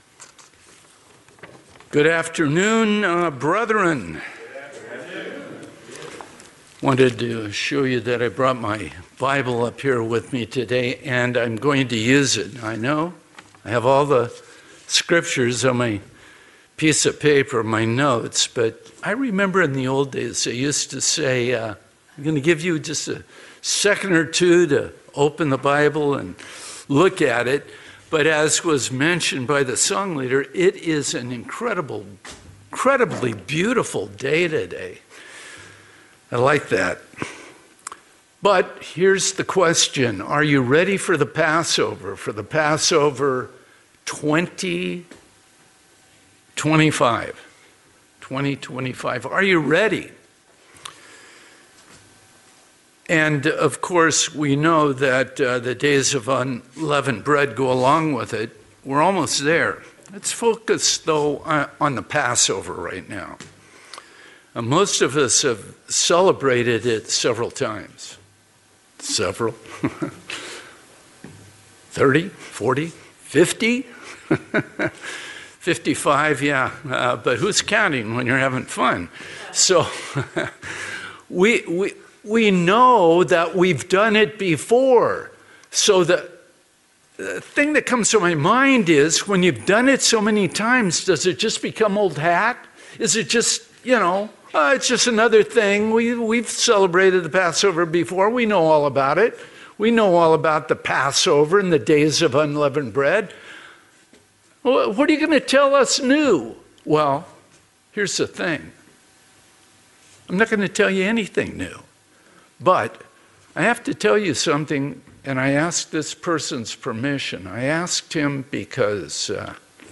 Sermons
Given in San Diego, CA Redlands, CA Las Vegas, NV